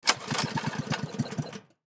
Карт не завелся утром